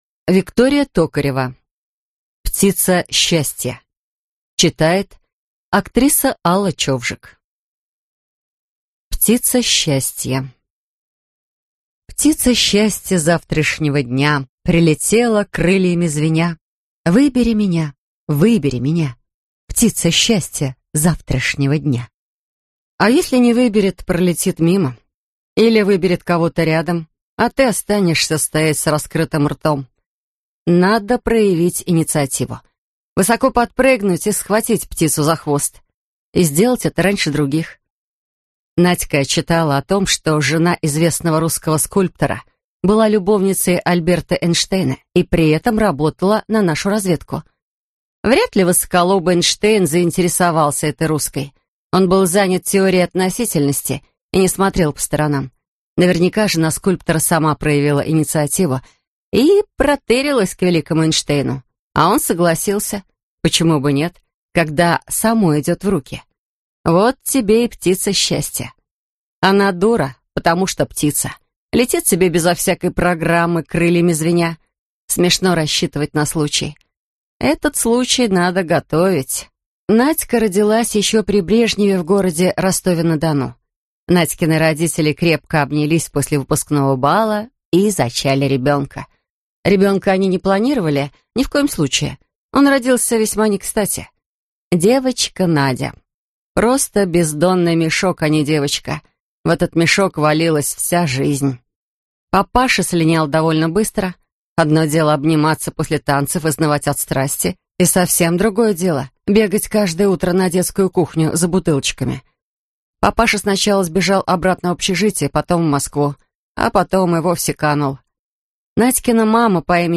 Аудиокнига Птица счастья (сборник) | Библиотека аудиокниг